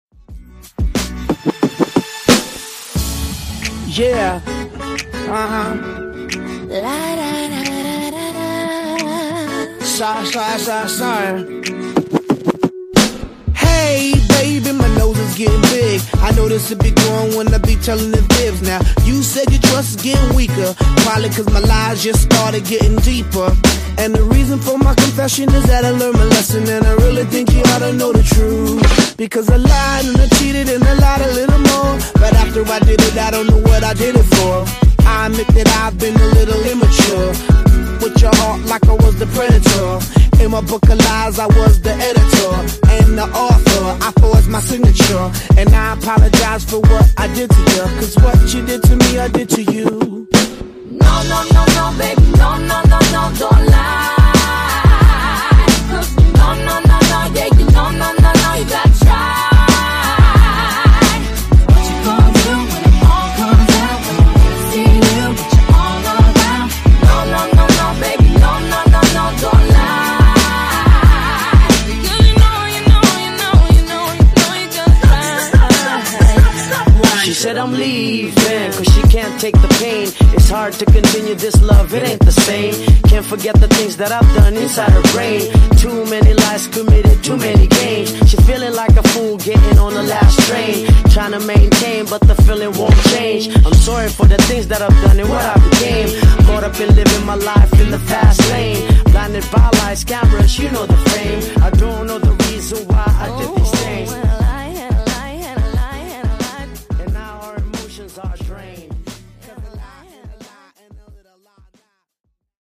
Genre: RE-DRUM
Clean BPM: 100 Time